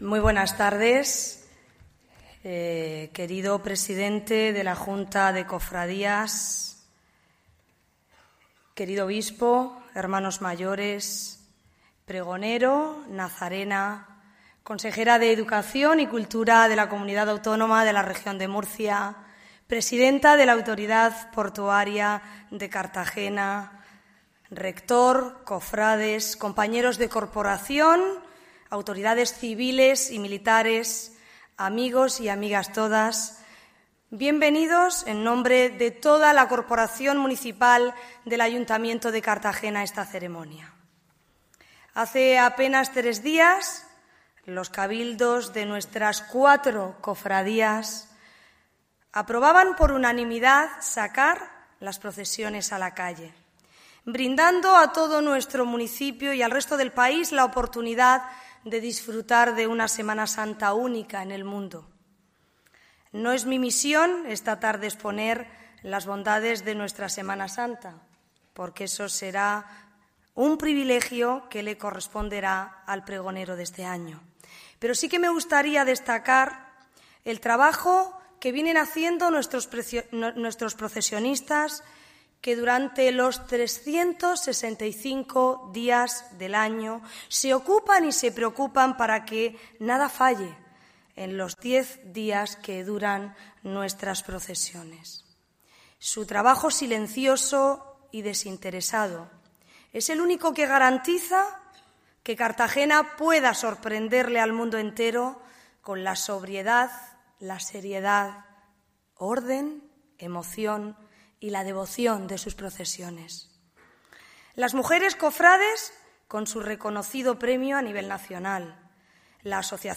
Preg�n de las Procesiones de Semana Santa 2020
El Nuevo Teatro Circo , ha acogido este sábado, día 29 de febrero , el pregón de Semana Santa de Cartagena.